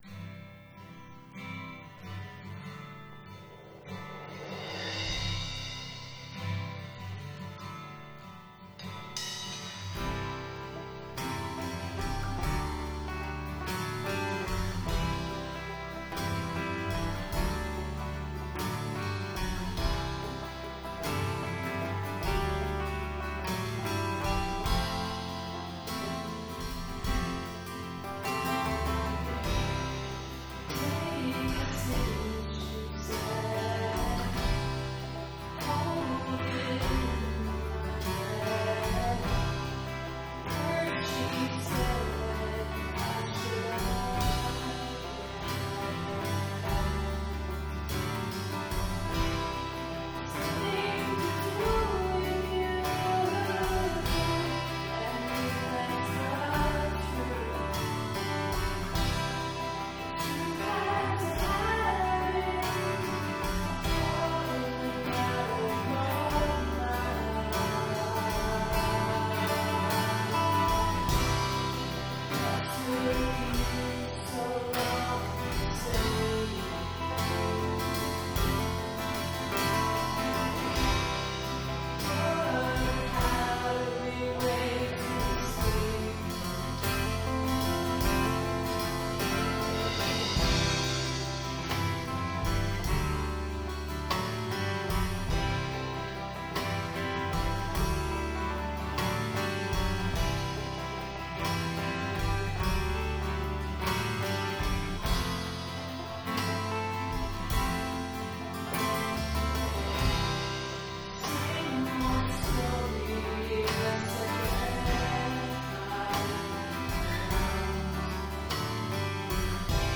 Smooth Rock & Natural Roll
electric guitar and vocals
accoustic guitar and vocals
bass and vocals
banjo
percussion
guitar and vocals